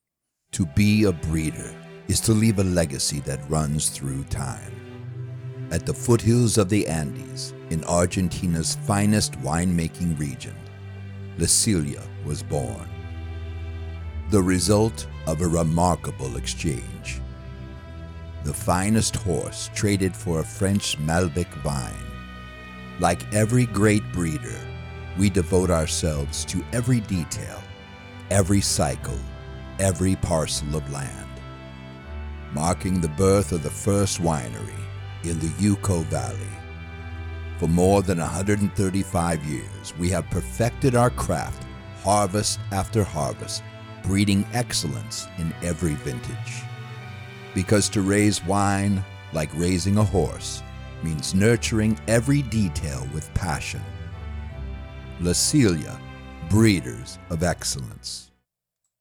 Male
Adult (30-50), Older Sound (50+)
Deep diverse, specializing in Characters from Sly Stallone to British Royalty
Smooth, articulate, funny, Radio Announcer, Movie Trailer, instructor
Radio Commercials